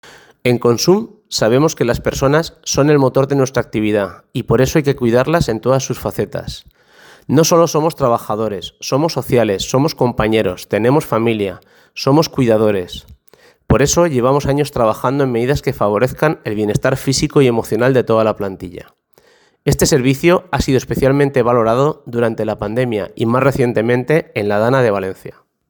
Sound bite